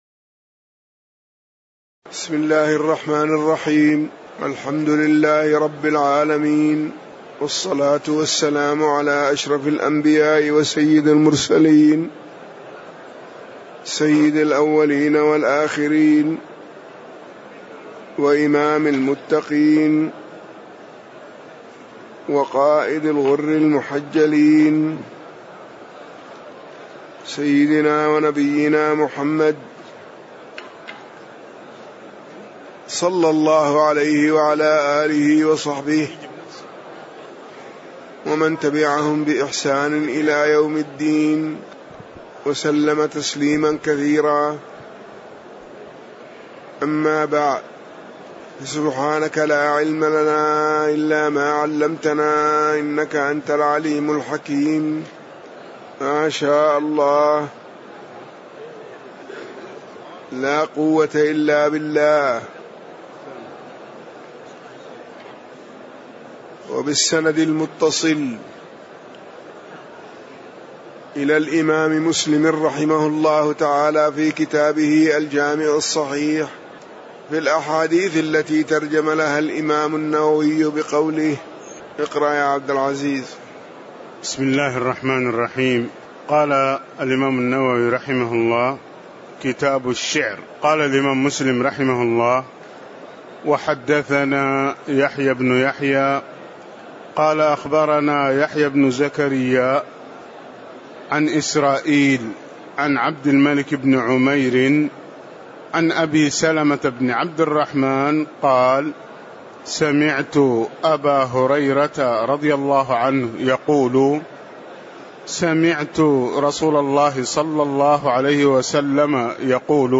تاريخ النشر ٨ ربيع الثاني ١٤٣٧ هـ المكان: المسجد النبوي الشيخ